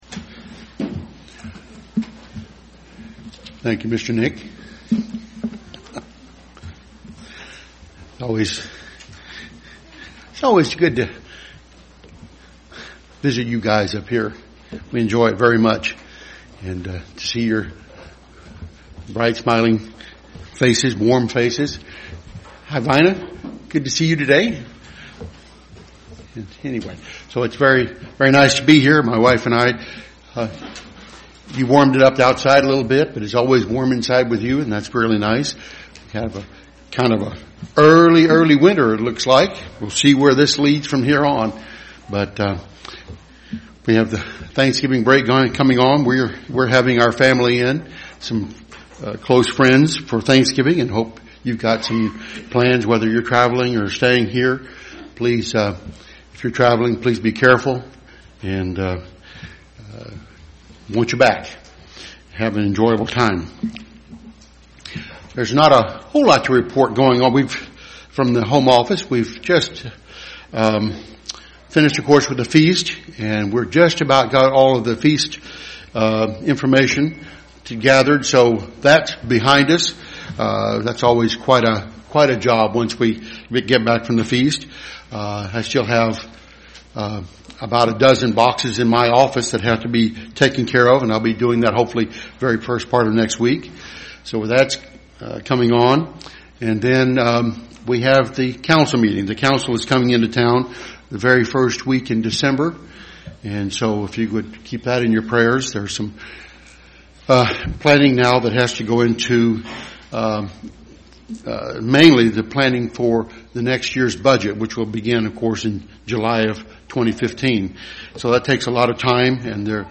Ruth, Rahab, Esther: These three women of the Bible have displayed great courage. This sermon looks into the courage they displayed and how they have forever changed the world.
Given in Dayton, OH